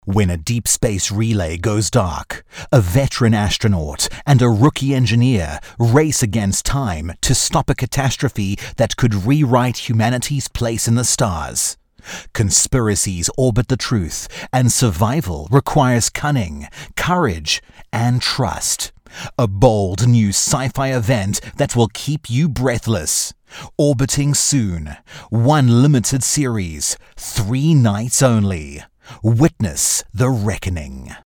Herzlich, sanft und vielseitig.